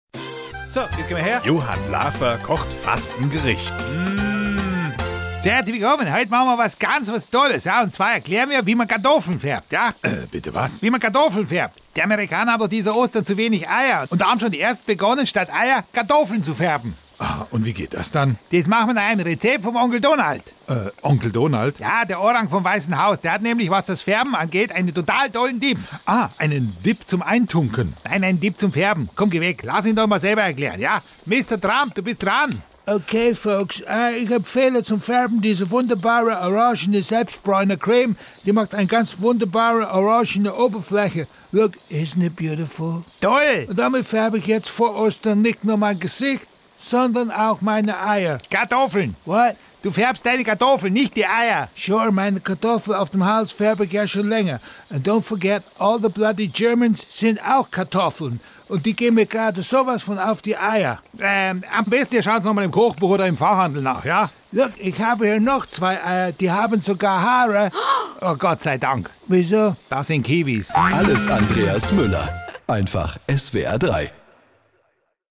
SWR3 Comedy Lafer kocht... Kartoffeln färben in Amerika